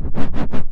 SAW RHYTHM.wav